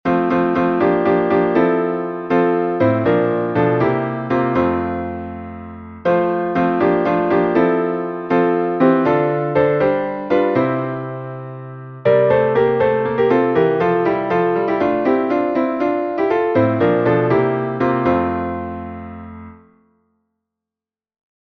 Dit is de nacht van de zwervers. vierstemmig – sopraan – alt – tenor – bas Blijf niet staren.